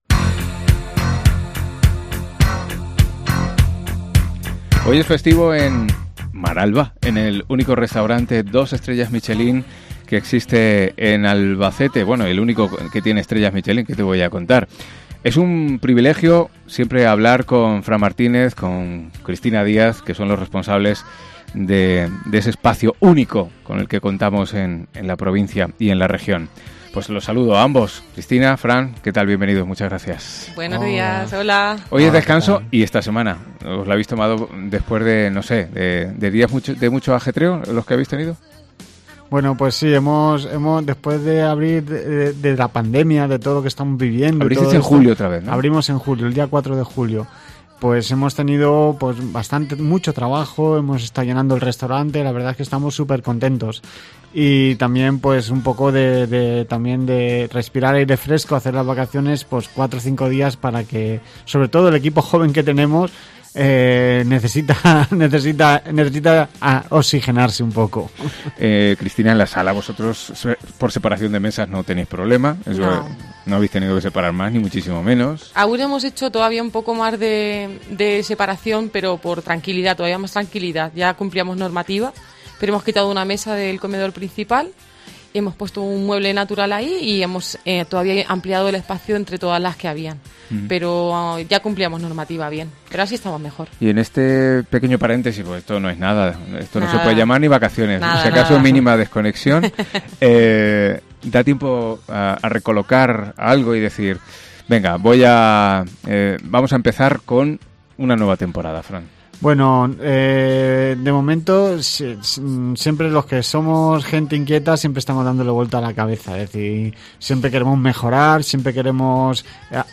San Pedro ENTREVISTA COPE Maralba